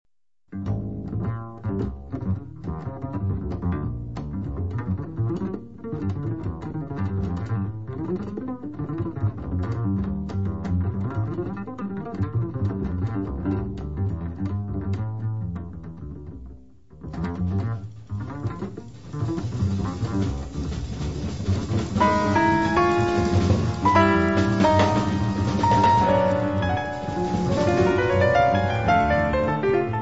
• jazz
• registrazione sonora di musica